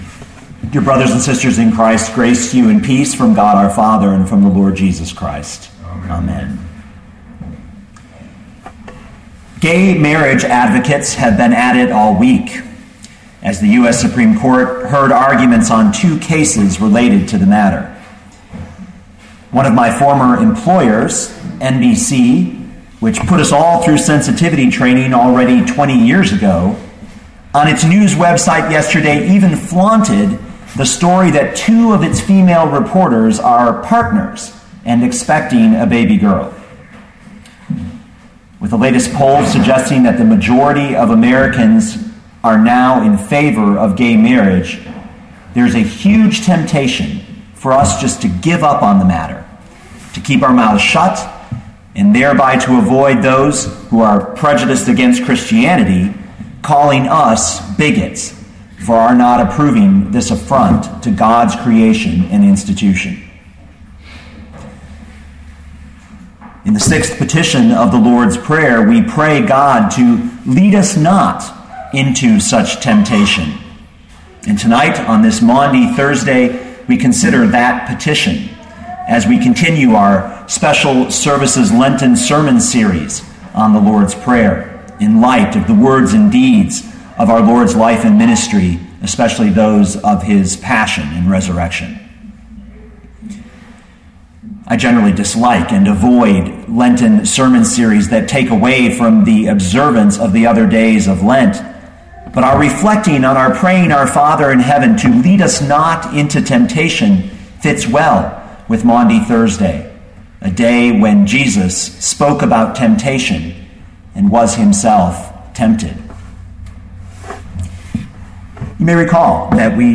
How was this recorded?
Maundy Thursday, March 28, 2013